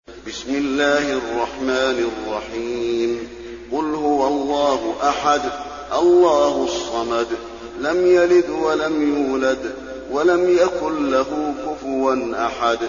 المكان: المسجد النبوي الإخلاص